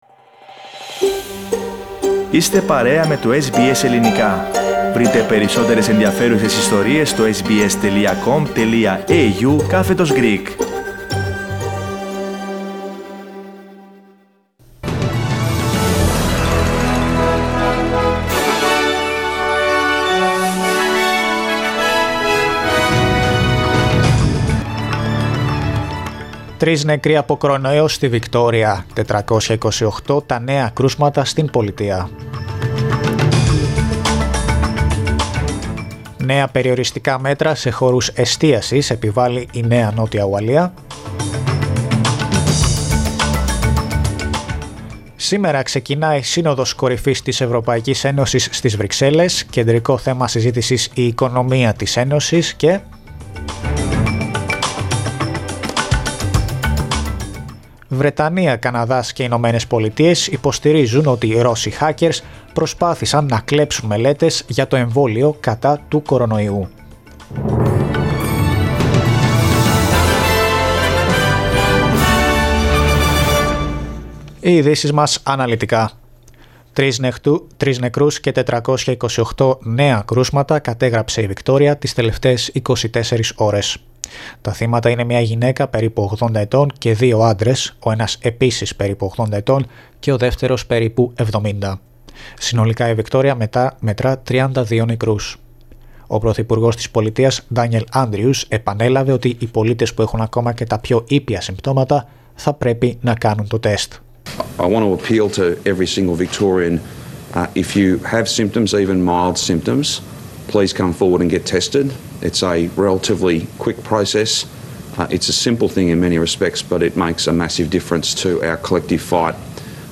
News from Australia, Greece, Cyprus and the world, in the News Bulletin of Friday 17th of July.